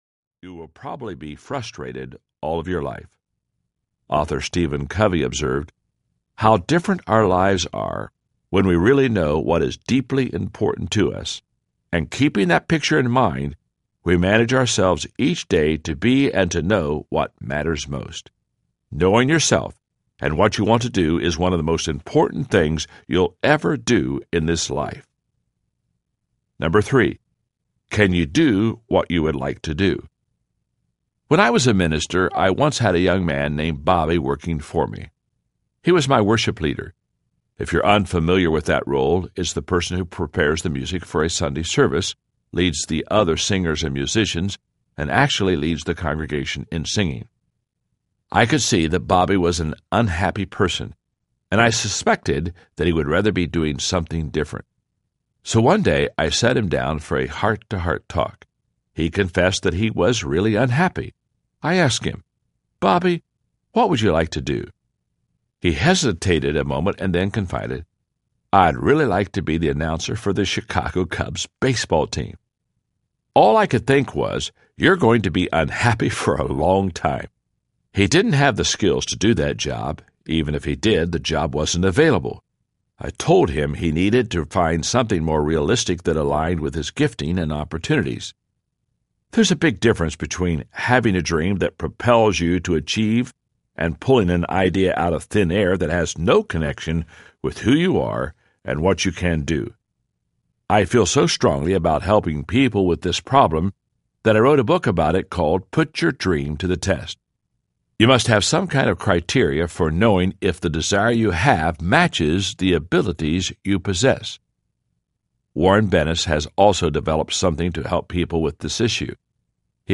The 15 Invaluable Laws of Growth Audiobook
Narrator
John C. Maxwell